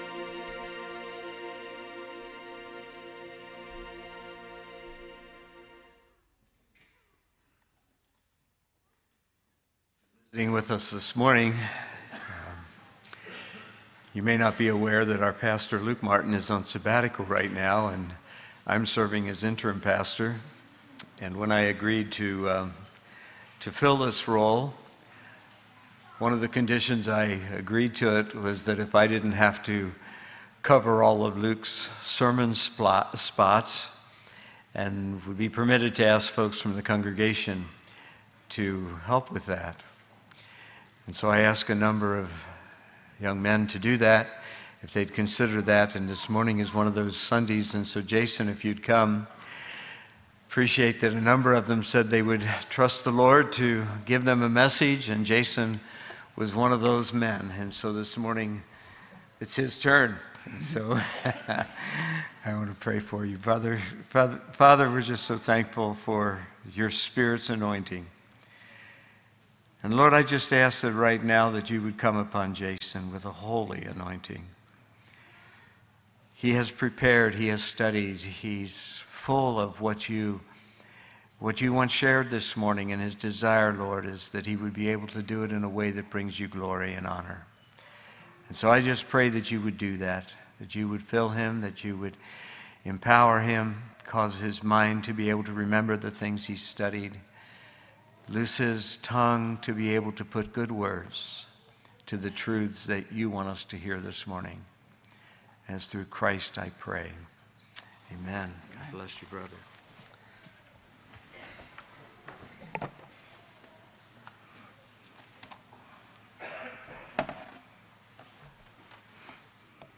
From Series: "Sunday Morning - 10:30"